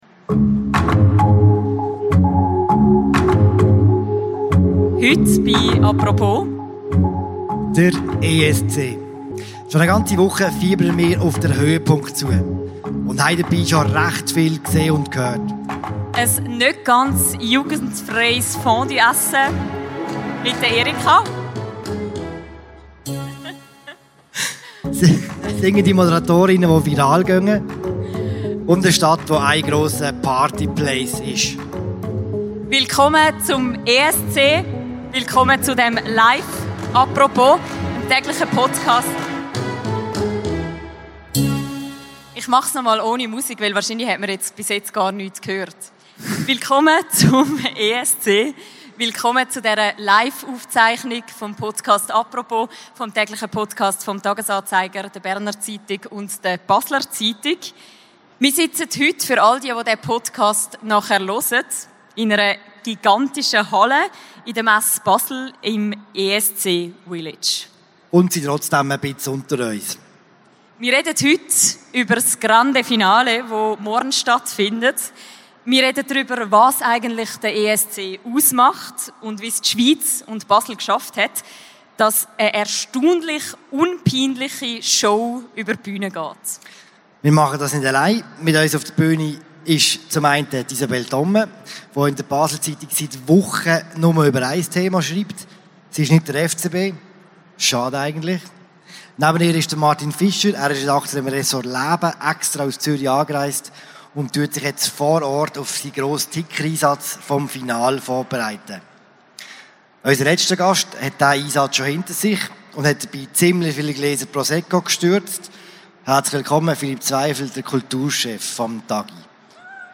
Der «Apropos»-Podcast aus dem Eurovision-Village zum Nachhören.
Live aus Basel: Alles zum ESC